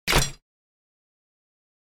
دانلود صدای ربات 73 از ساعد نیوز با لینک مستقیم و کیفیت بالا
جلوه های صوتی